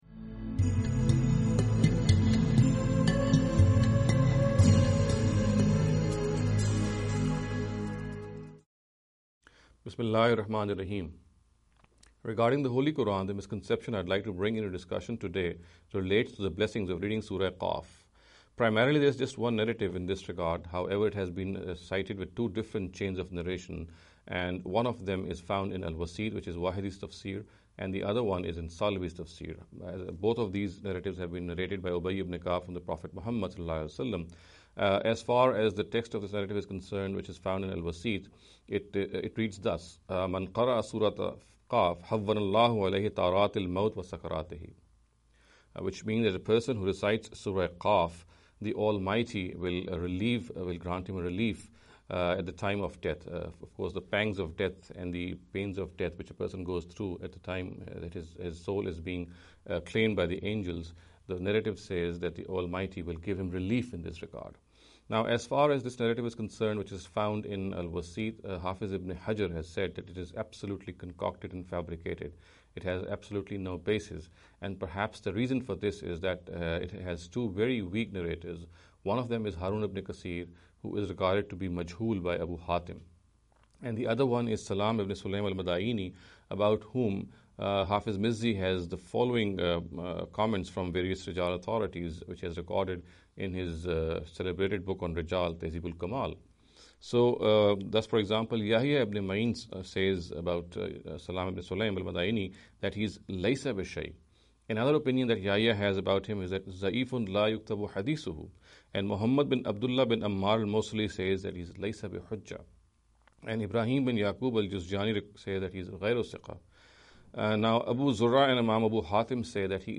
In every lecture he will be dealing with a question in a short and very concise manner.